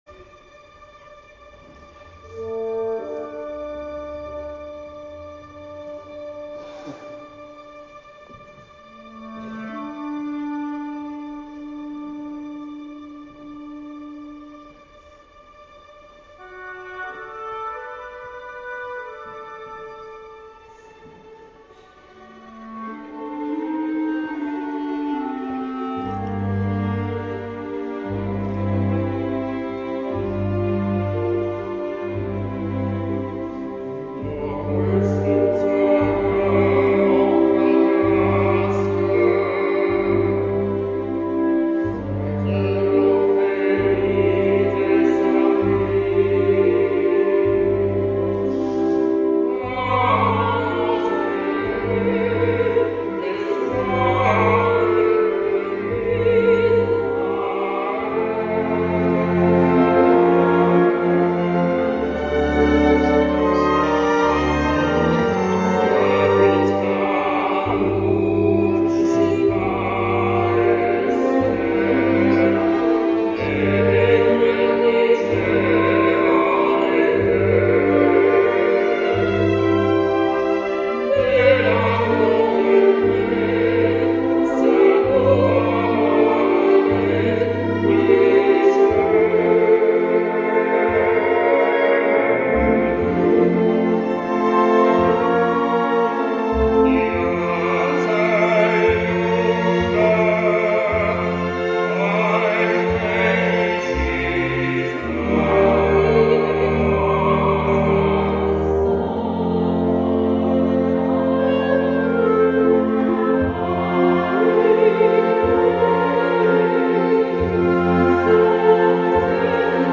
Concertul de la  Sinagoga din Cetate
The Opera concert at the Synagogue - The Concert